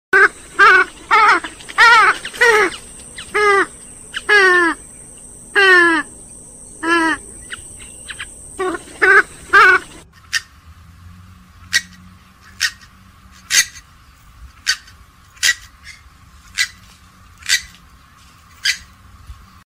POLLO-SULTANO-Porphyrio-porphyrio.mp3